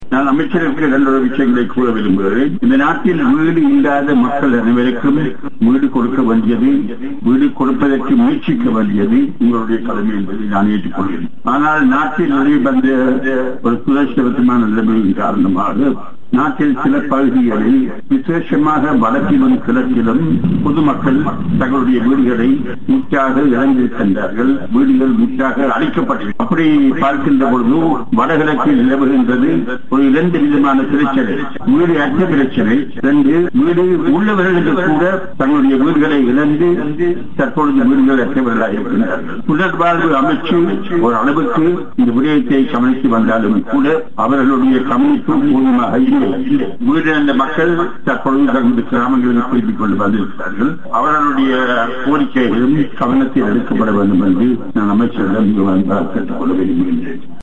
திருகோணமலையில் நேற்று இடம்பெற்ற நிகழ்வில் கலந்து கொண்டு உரையாற்றும் போதே அவர் இந்த குற்றச்சாட்டை முன்வைத்தார்.